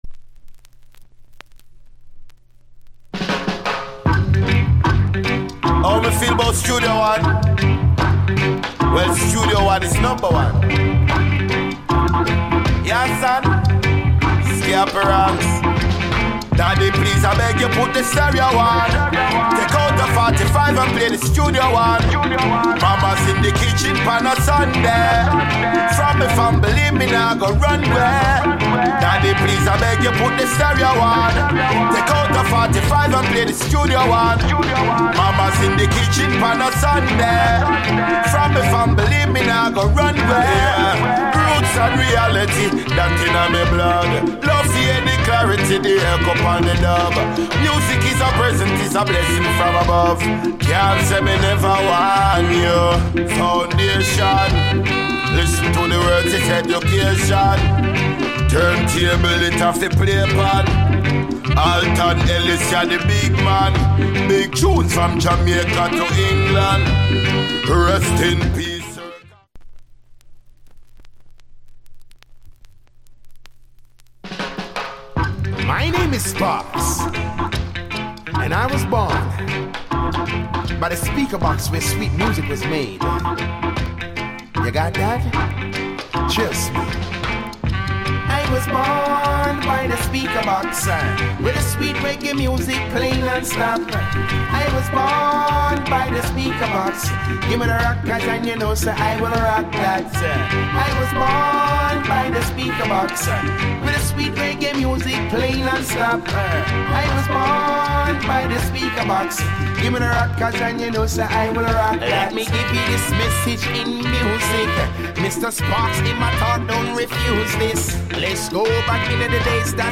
Genre ReggaeAfter90s / [A] Male DJ [B] Male DJ